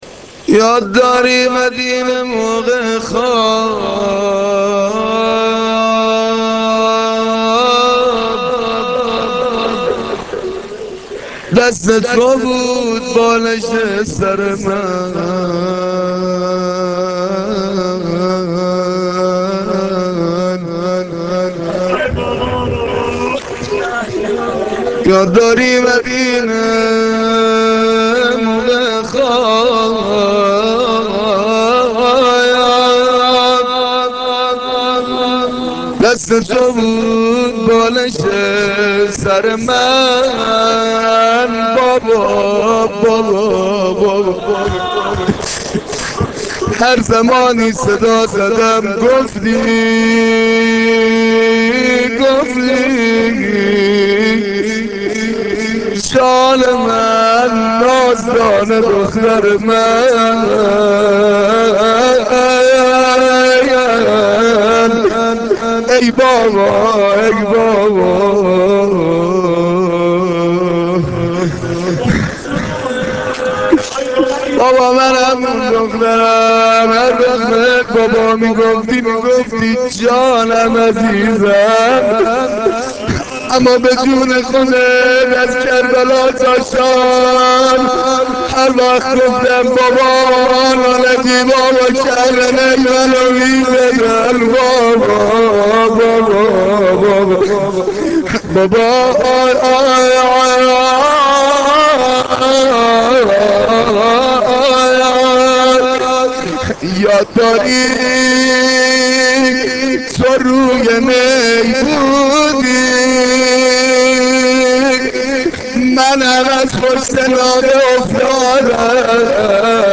مداحی روضه شب سوم